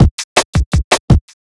HP082BEAT1-L.wav